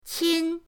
qin1.mp3